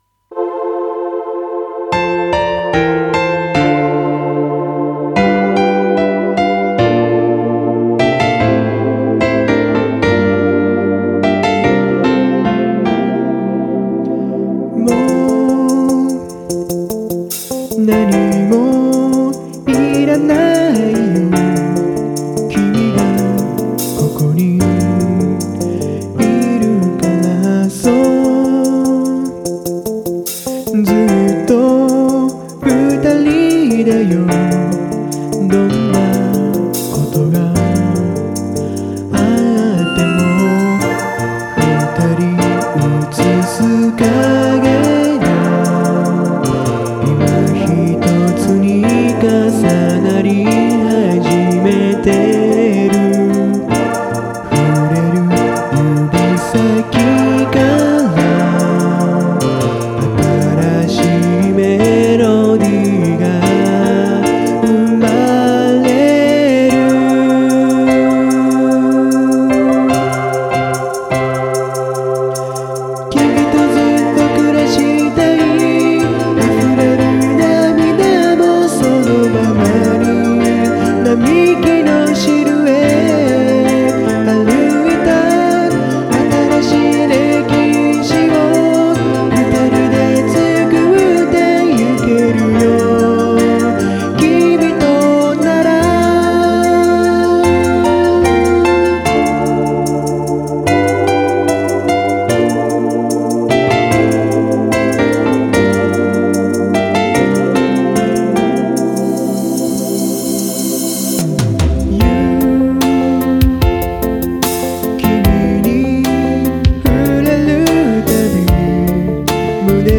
音源：KORG X3
MTR：YAMAHA CMX100ⅢS
エフェクター：YAMAHA EMP100
DAW(Remastering)：SONAR7 Producer Edition